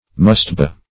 Mustaiba \Mus`ta*i"ba\, n.